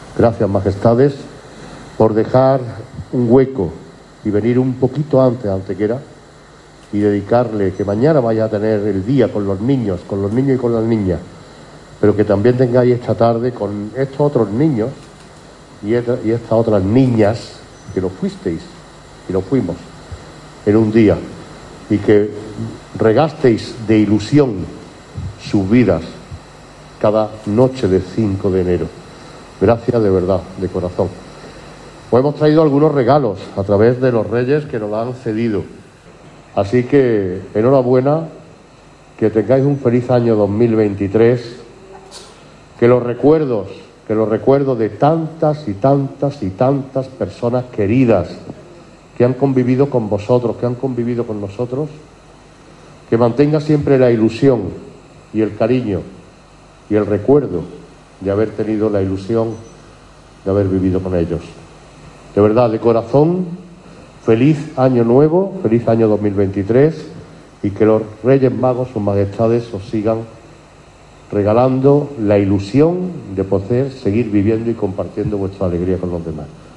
El Alcalde ofreció, tanto a Sus Majestades como al resto del séquito real, unas palabras de bienvenida en la residencia de ancianos de San Juan de Dios que estuvieron dedicadas a los internos del centro: “Gracias, Majestades, por haber venido antes a Antequera a ver a estos otros niños y otras niñas, que lo fueron, a los que también regasteis de ilusión sus vidas. Que Sus Majestades os sigan regalando la ilusión de poder seguir viviendo y compartiendo vuestra alegría con los demás”.
Cortes de voz